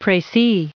added pronounciation and merriam webster audio
1009_précis.ogg